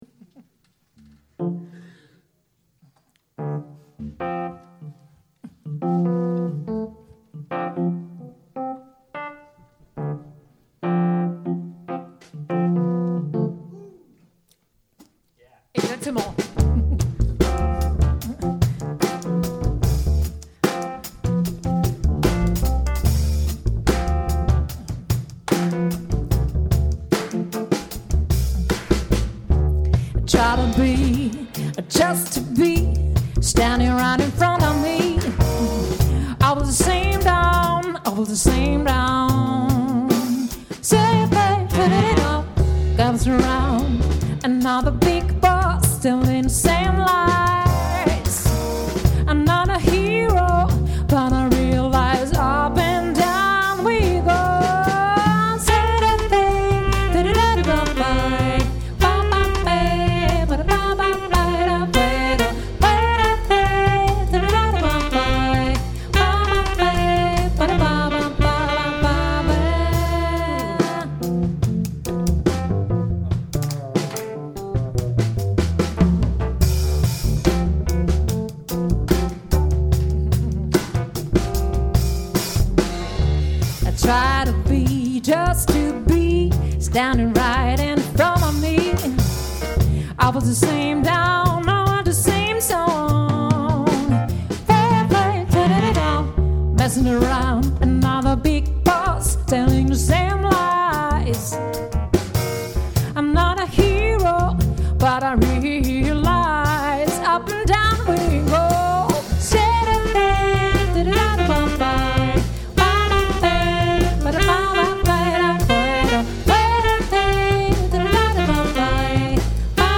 d’abord en Trio puis en quartet.